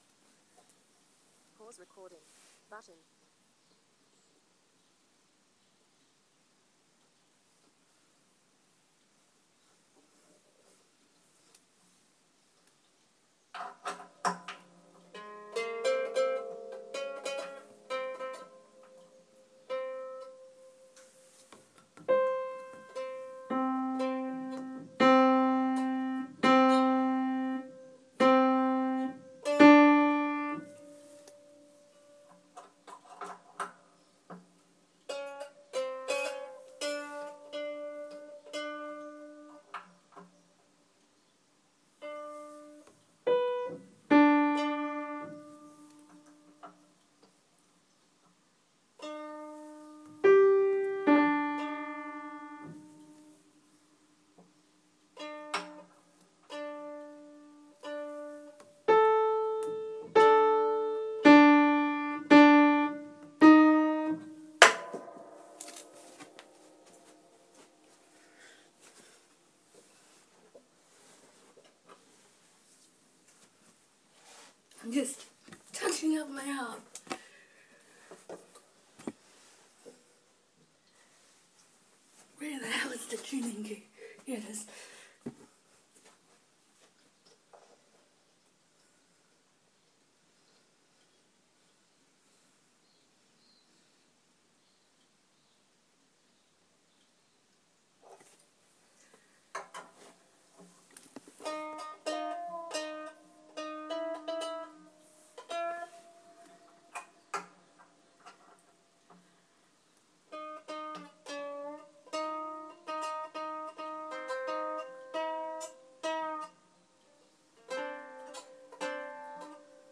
Harp tuning